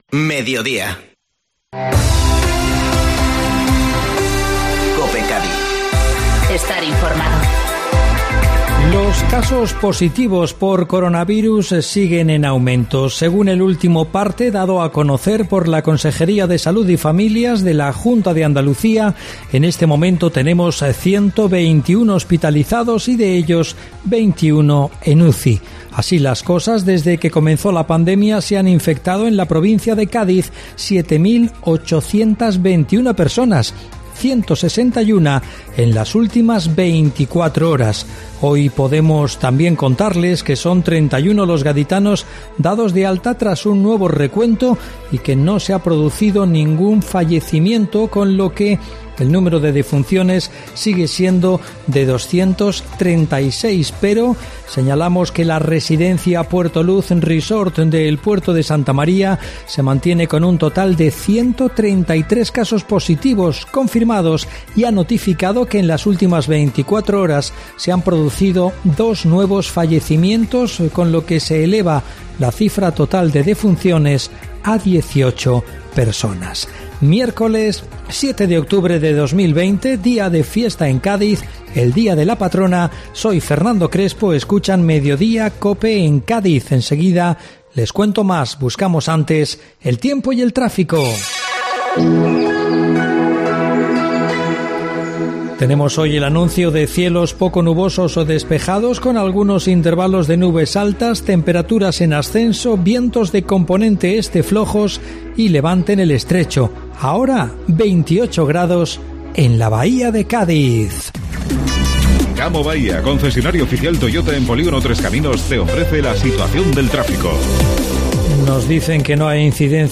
Informativo Mediodía COPE Cádiz (7-10-20)